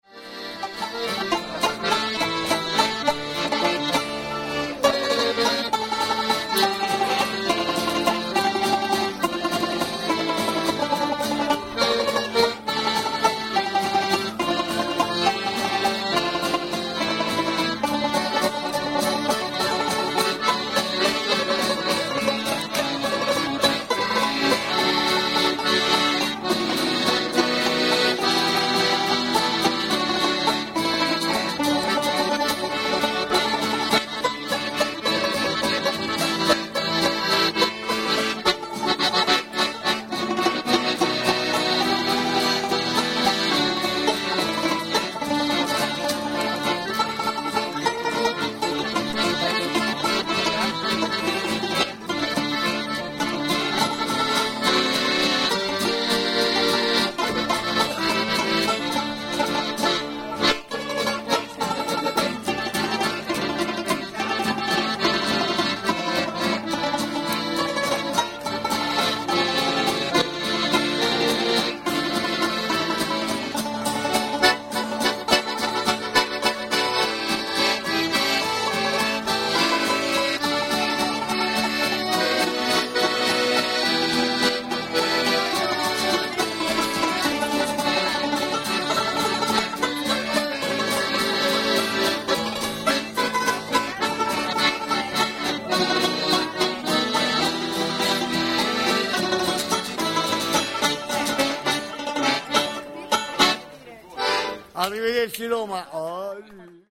playing outside his Bar this evening
Jazz Trio
guitar
Banjo Mandolin